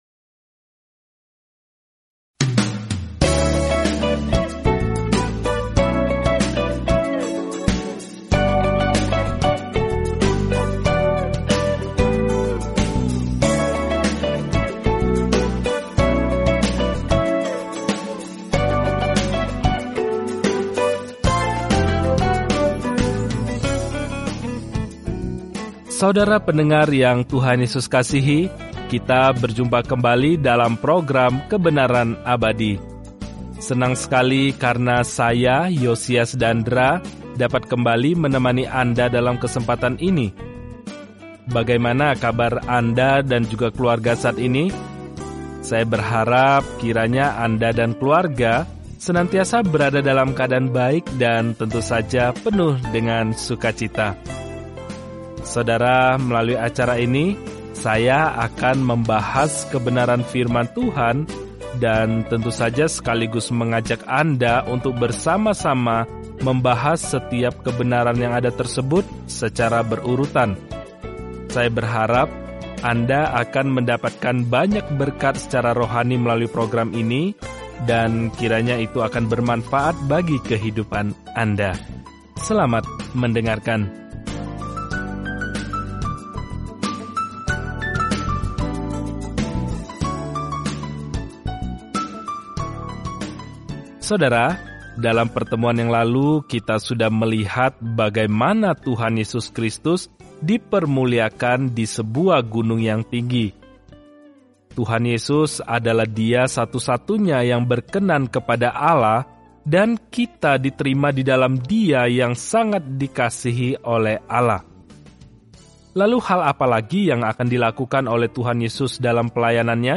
Firman Tuhan, Alkitab Matius 18 Hari 25 Mulai Rencana ini Hari 27 Tentang Rencana ini Matius membuktikan kepada para pembaca Yahudi kabar baik bahwa Yesus adalah Mesias mereka dengan menunjukkan bagaimana kehidupan dan pelayanan-Nya menggenapi nubuatan Perjanjian Lama. Telusuri Matius setiap hari sambil mendengarkan studi audio dan membaca ayat-ayat tertentu dari firman Tuhan.